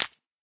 ignite.ogg